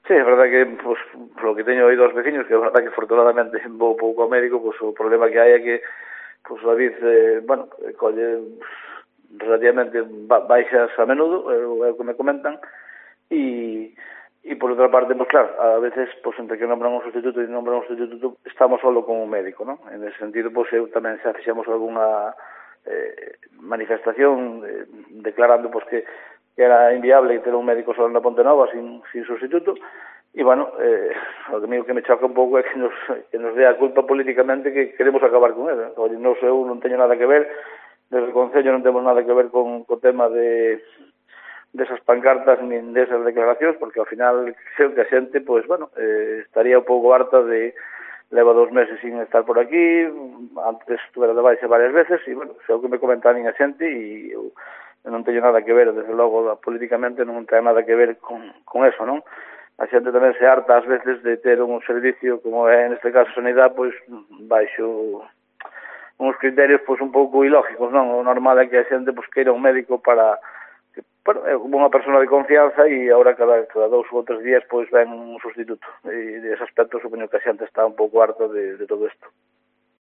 Declaraciones de DARÍO CAMPOS, alcalde de A Pontenova